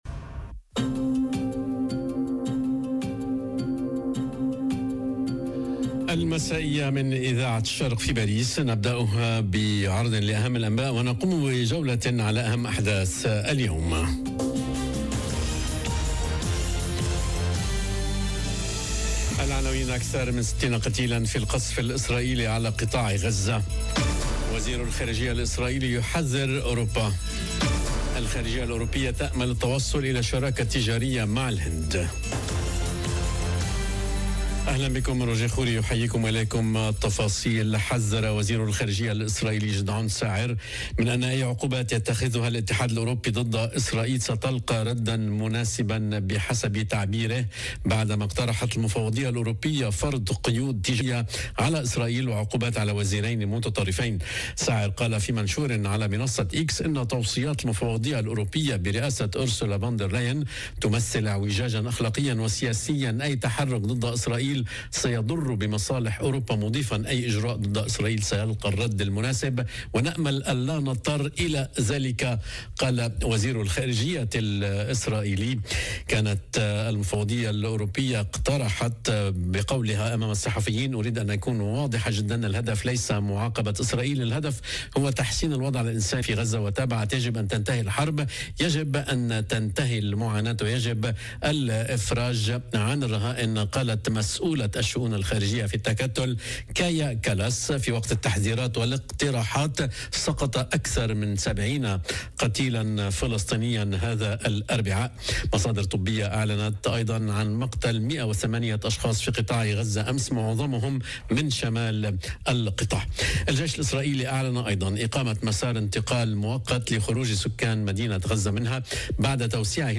نشرة أخبار المساء: وفيات في غزة بينهم أطفال، وبريطانيا ترحل أول مهاجر إلى فرنسا - Radio ORIENT، إذاعة الشرق من باريس